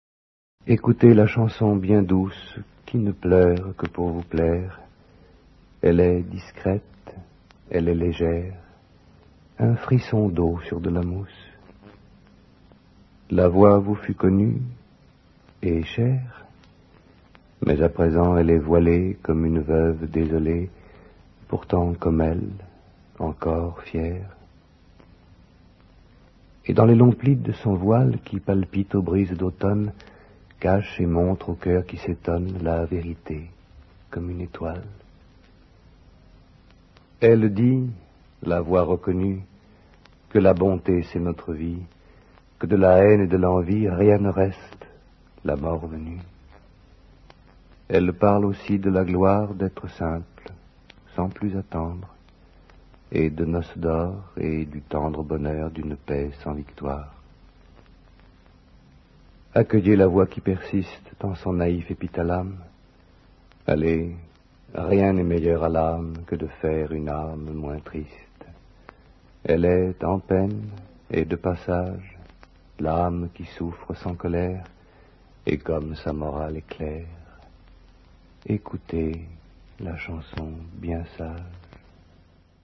dit par Jean-Claude PASCAL